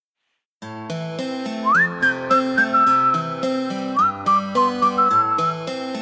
That One Josh Hutcherson Whistle Sound Effect Free Download